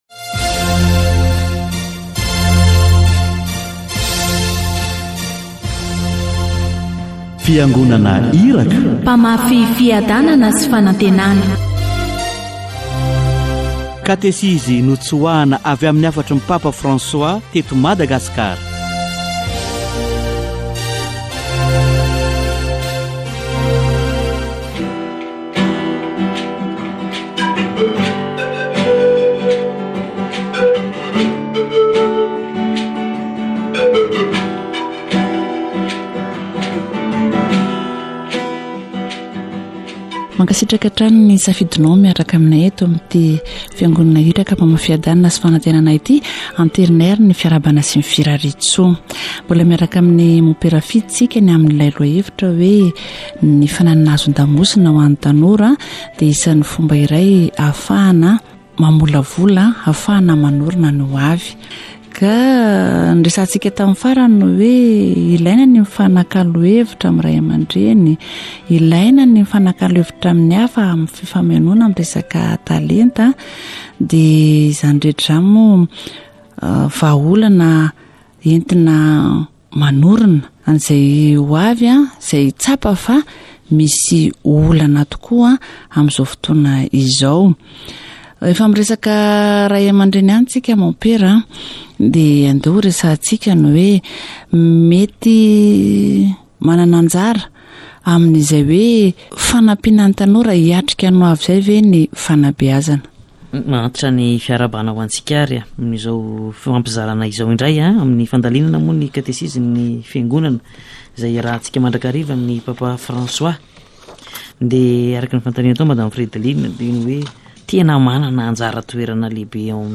Jesus showed us the effective way to find joy and hope in the future. Catechesis on personality